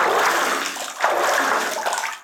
WaterSplash_Medium.wav